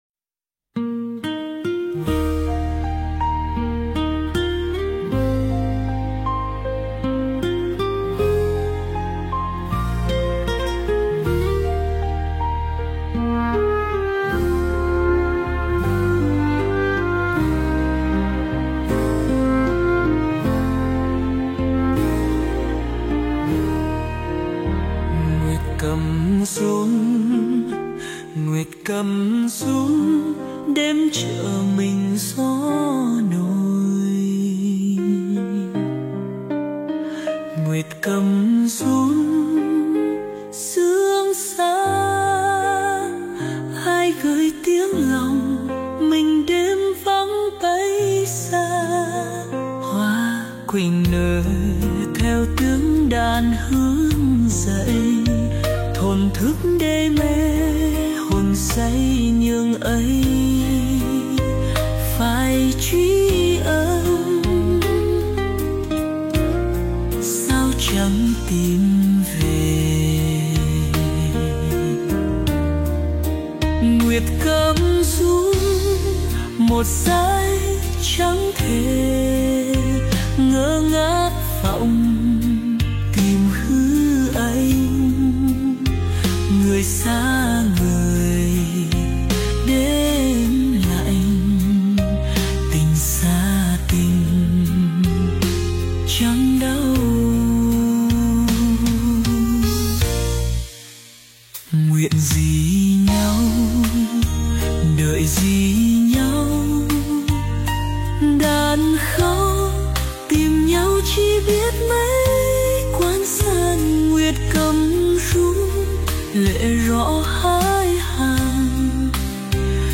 Nhạc thiền - Vị Lai Pháp
466-nha-c-thie-n.mp3